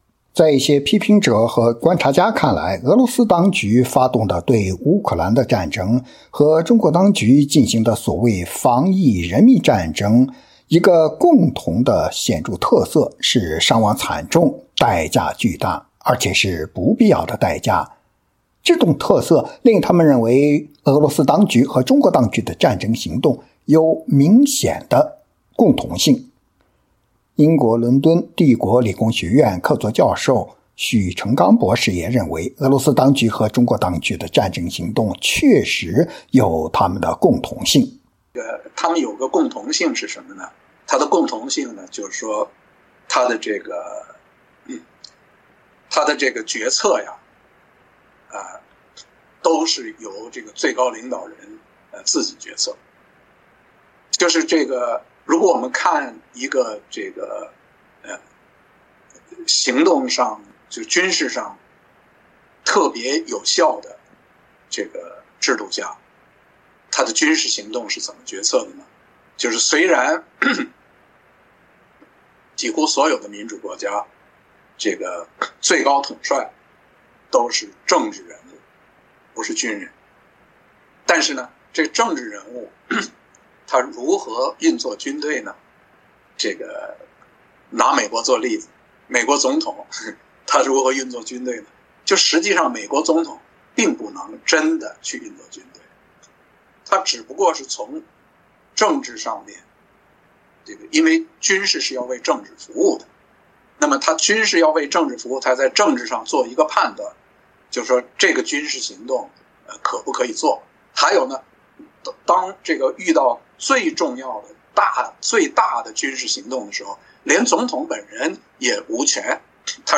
英国伦敦帝国理工学院客座教授许成钢博士在接受美国之音采访时表示，这种相似性值得认真分析。